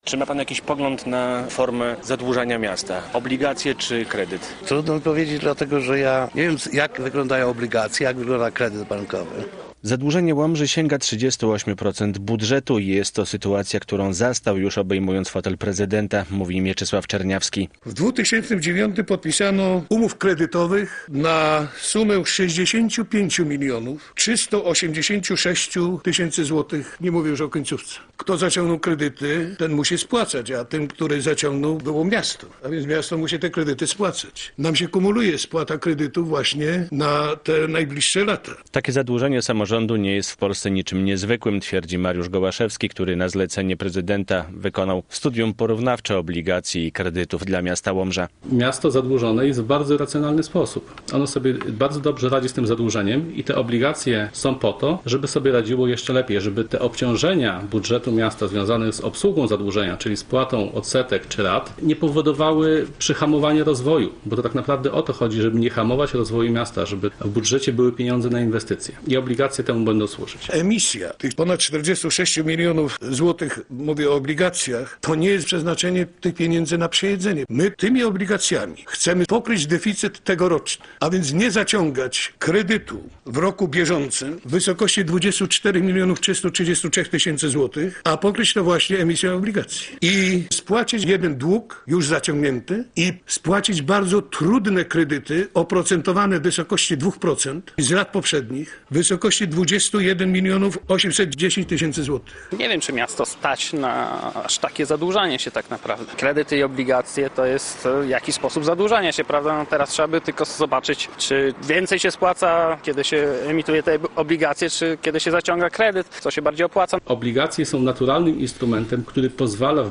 Obligacje zamiast kredytów - relacja